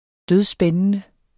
Udtale [ ˈdøðˈsbεnənə ]